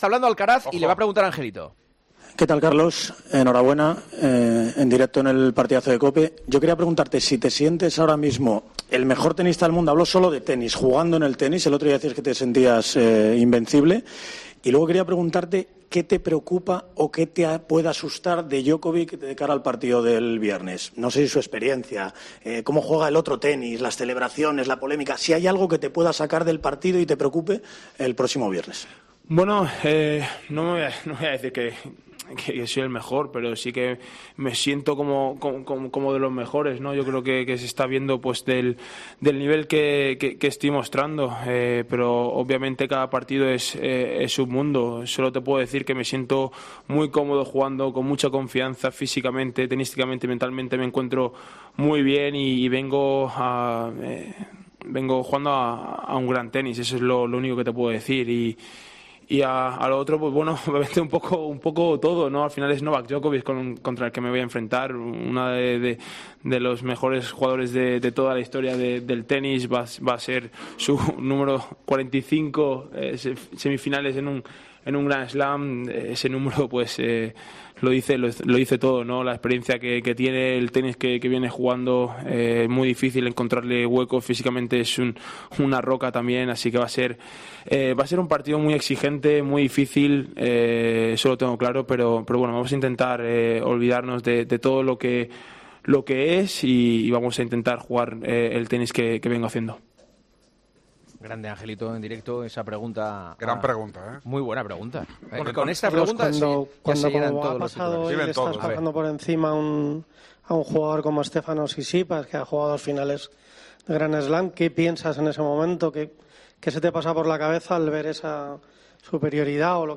El tenista español valoró su victoria en rueda de prensa y expresó su deseo de jugar ya contra Djokovic en las semifinales de Roland Garros.